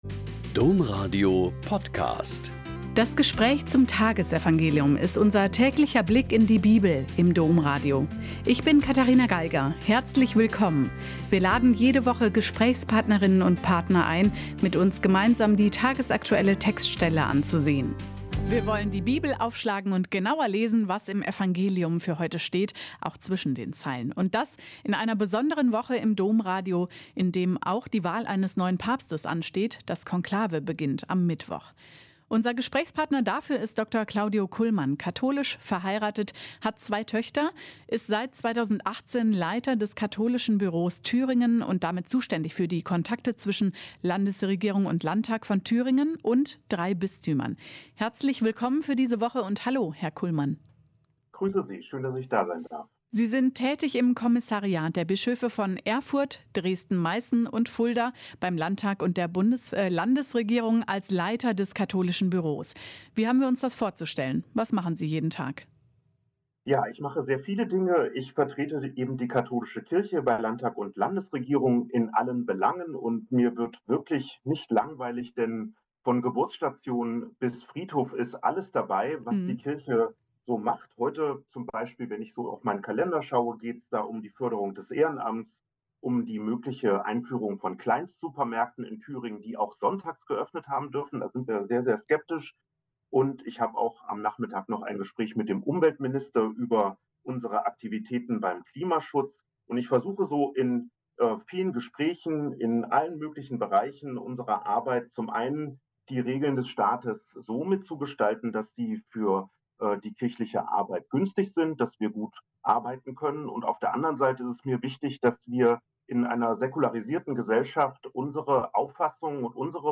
Joh 6,22-29 - Gespräch